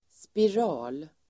Uttal: [spir'a:l]